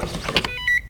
vehicleswipeid.ogg